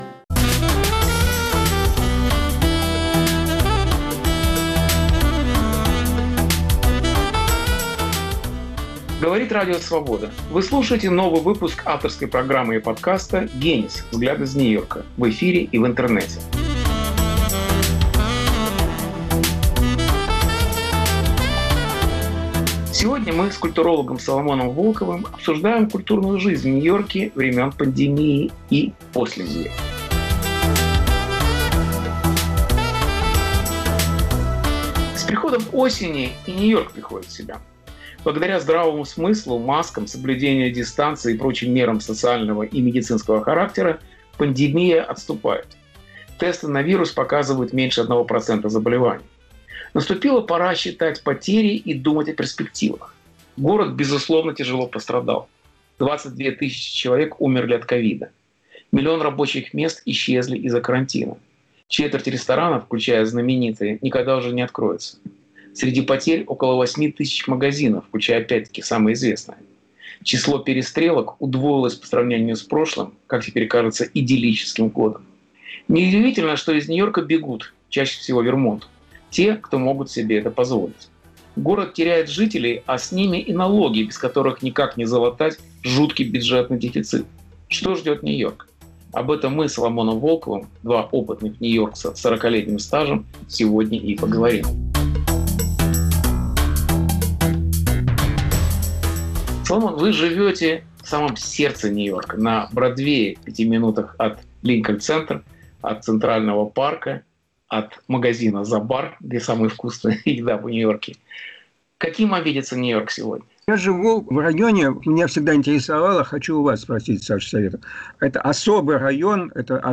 Беседа с Соломоном Волковым о городской жизни времен пандемии